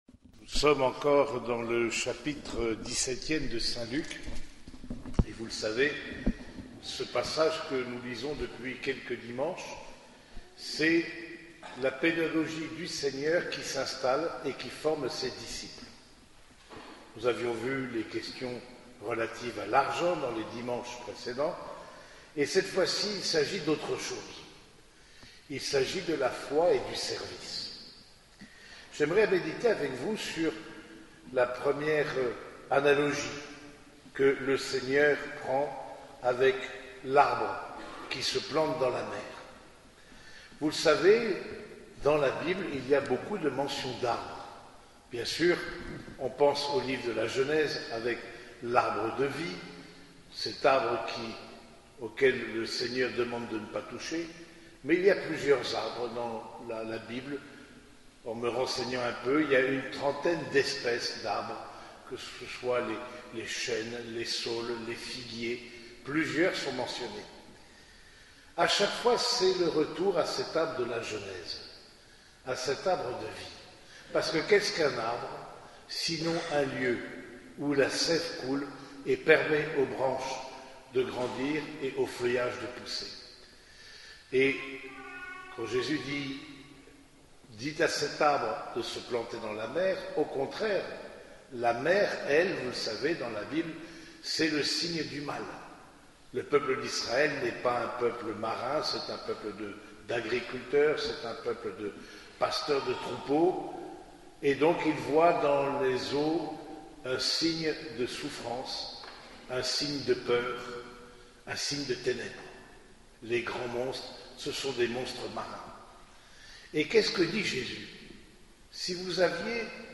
Homélie du 27e dimanche du Temps Ordinaire
Cette homélie a été prononcée au cours de la messe dominicale célébrée à l’église Saint-Germain de Compiègne.